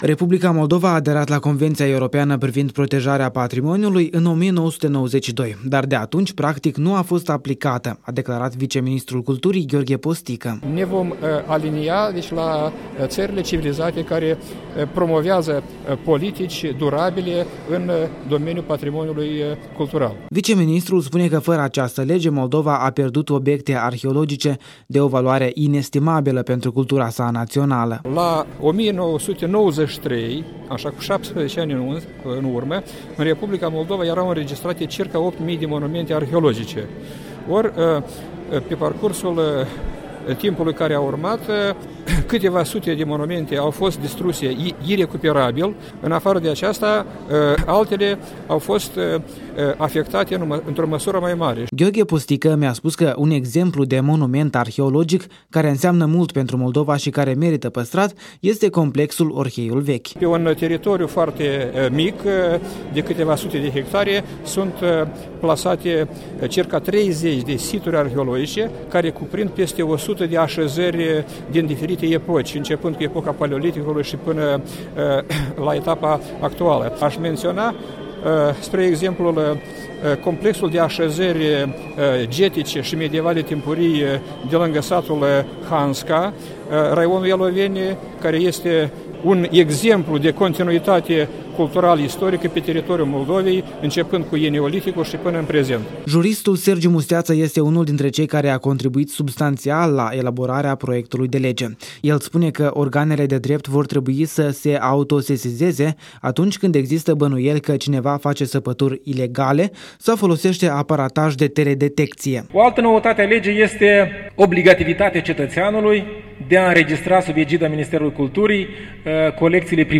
O relatare de la reuniunea organizată de Ministerul Culturii în colaborare cu Muzeul Național de Arheologie și Asociația tinerilor istorici